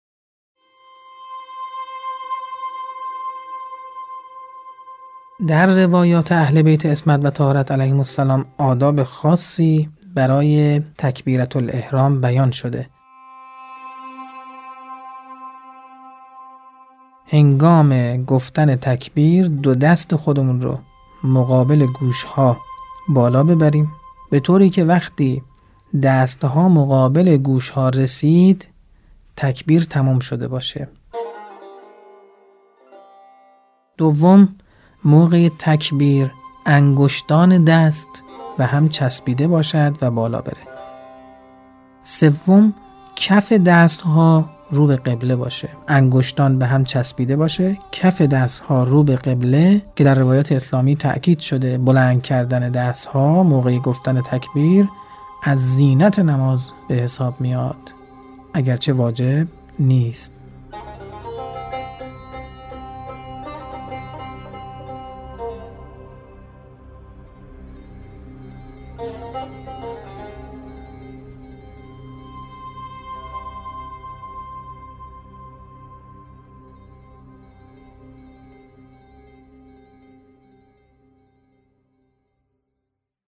نواهنگ